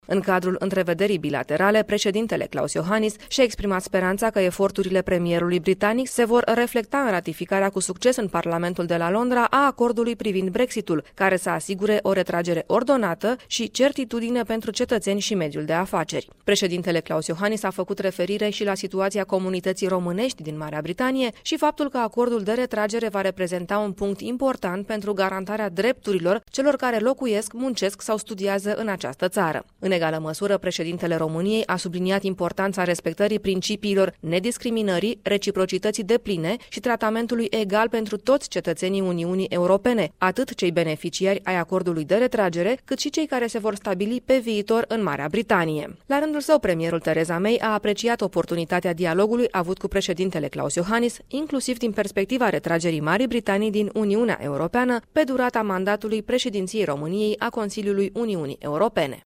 Discuţiile dintre cei doi demnitari au avut loc în contextul întrunirii Consiliului European de la Bruxelles.